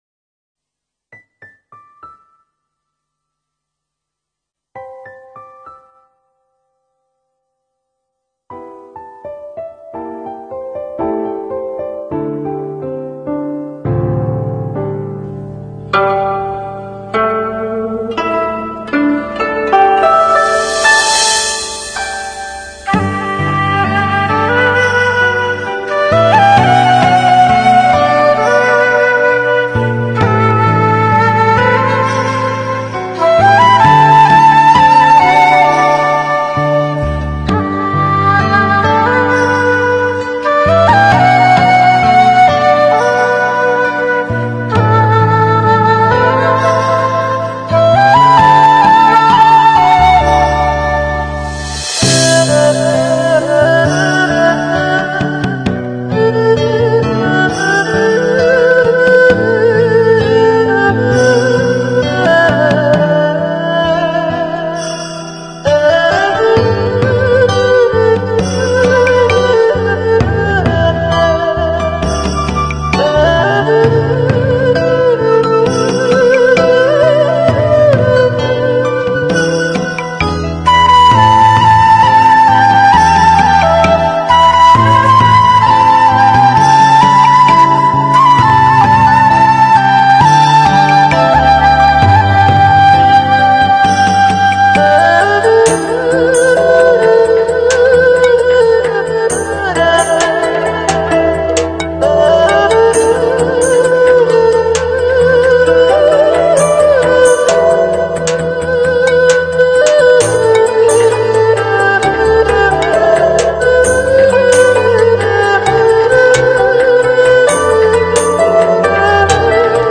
全新改编乐器演奏国语经典老歌 纯音乐HiFi轻音乐